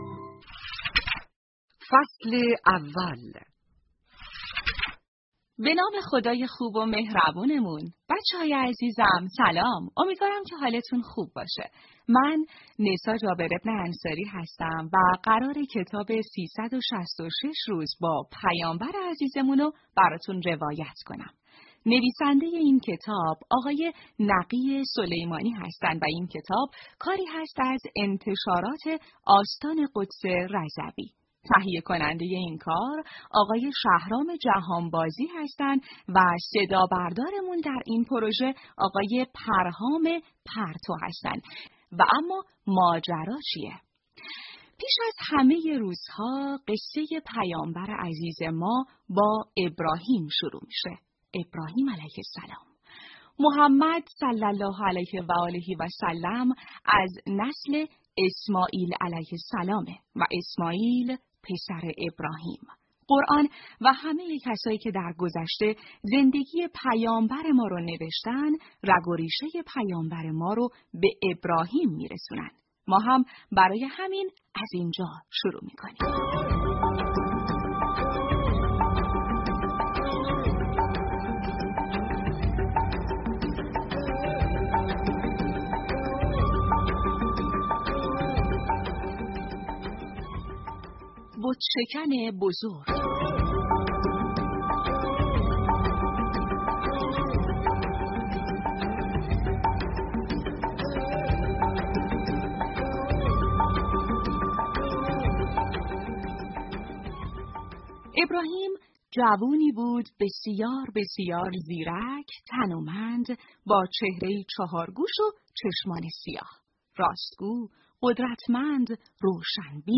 کتاب صوتی «۳۶۶ روز با پیامبر عزیزمان»فصل اول
# کتاب صوتی # قصه # قصه کودک